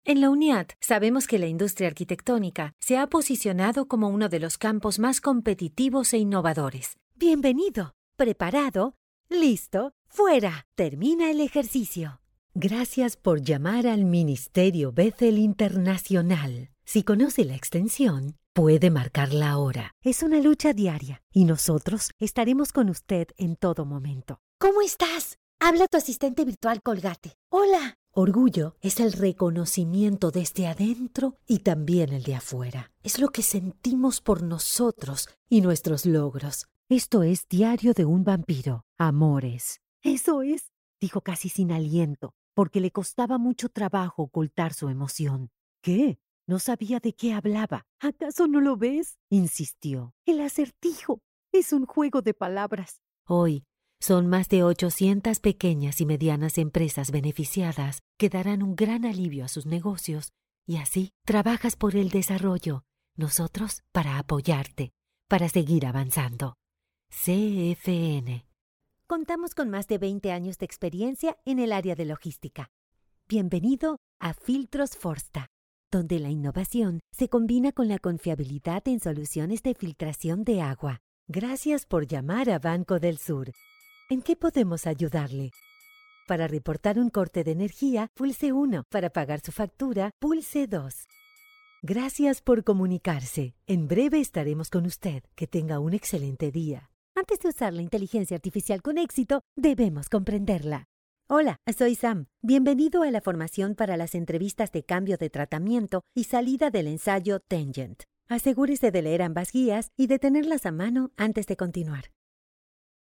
Spanish (Argentina)
Adult (30-50) | Older Sound (50+)
0930NARRACIO__N_E-L_CORP_IVR_NEUTRO.mp3